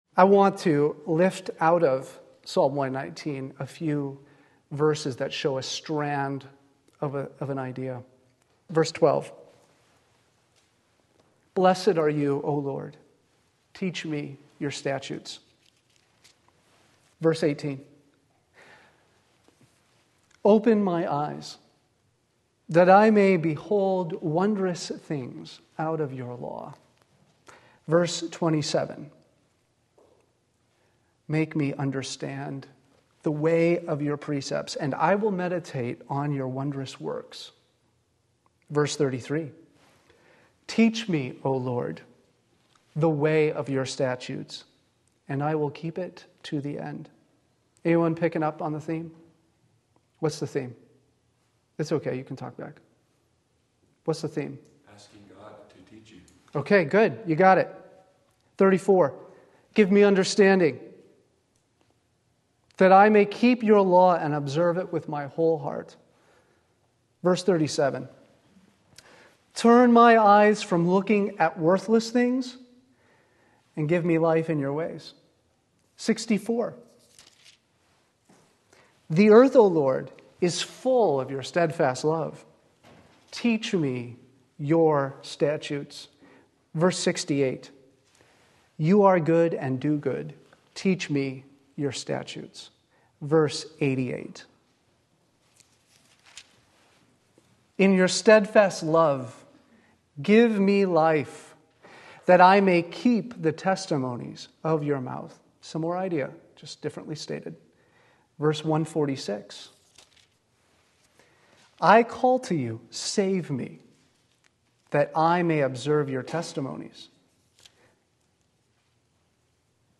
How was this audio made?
The Shining Face of God in Scripture Psalm 119:135 Sunday Afternoon Service